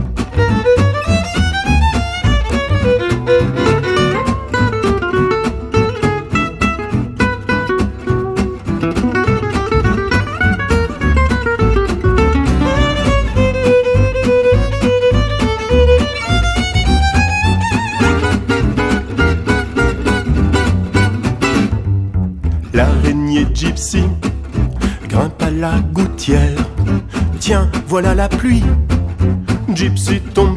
cinq musiciens de jazz manouche.